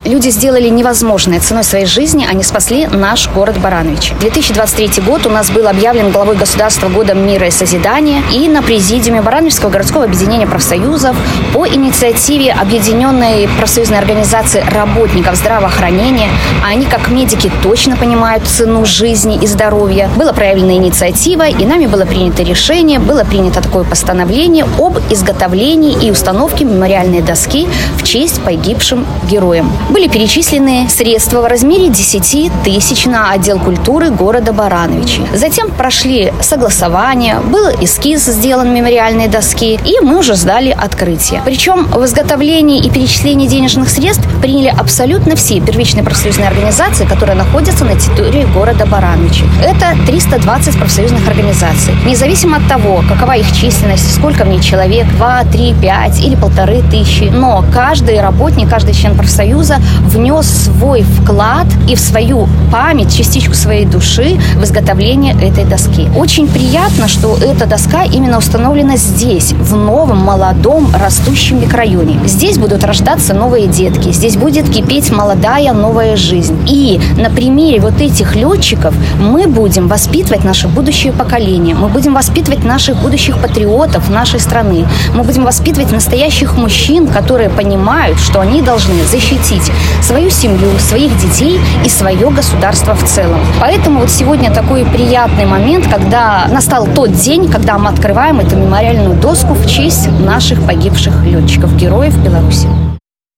Сегодня в новом микрорайоне Северный-2 на улице майора Ничипорчика открыли мемориальную доску в честь погибших лётчиков, которая расположилась на доме №10.